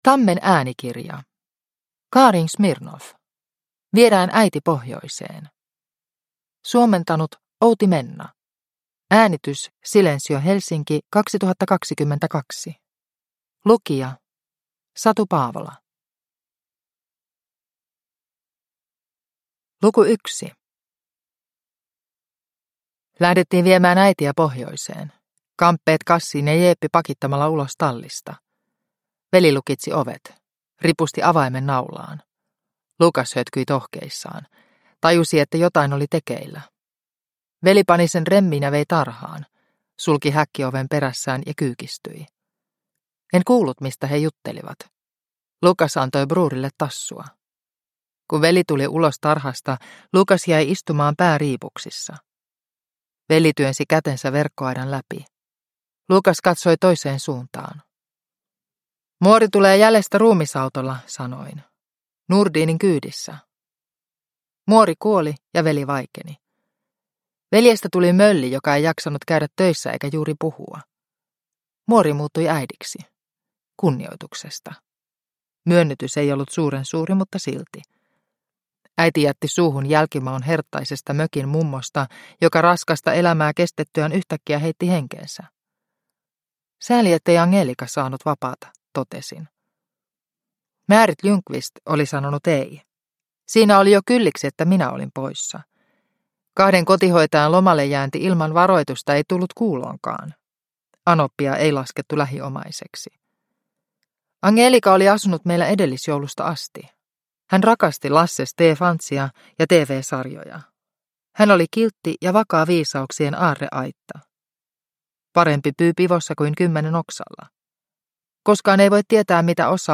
Viedään äiti pohjoiseen (ljudbok) av Karin Smirnoff